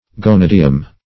Gonidium \Go*nid"i*um\, n. [NL., fr. Gr. ?, dim. of ? angle.]